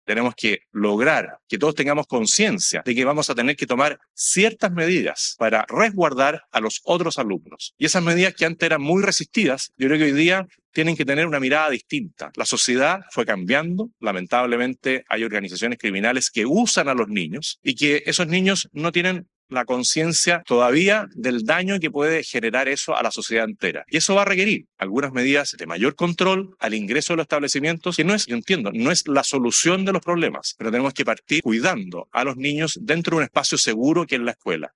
Durante su participación en el Encuentro de Directores por Chile, organizado por la ministra de Educación, María Paz Arzola, el Mandatario sostuvo que es necesario avanzar en medidas de protección para las comunidades escolares.